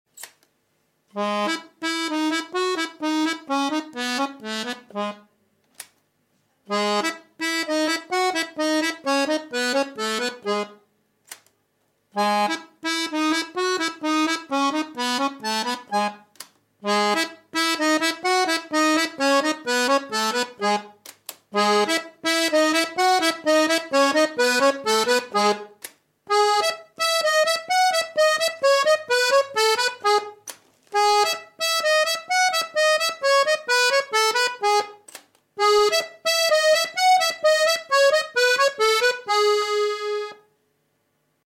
Registers on accordion.
Which one Mp3 Sound Effect Registers on accordion. Which one is better?